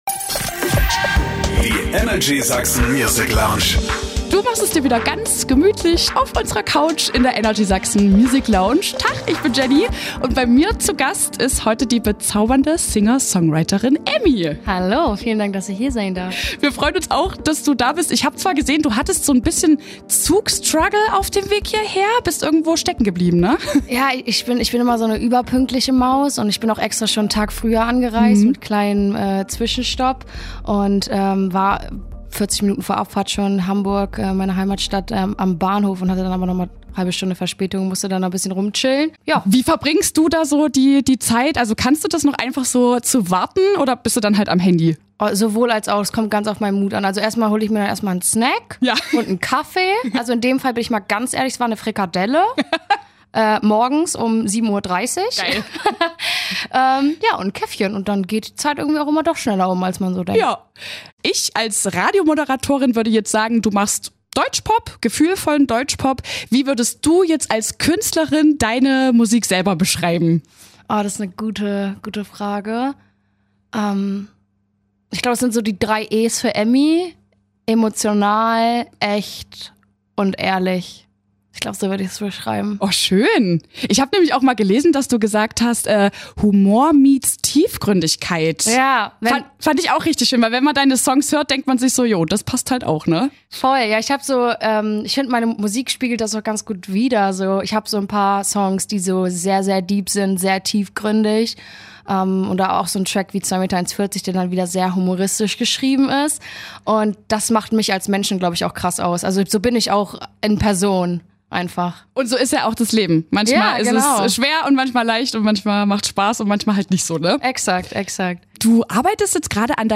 Freu dich auf ein lustiges Interview, mit einer richtig coolen Socke.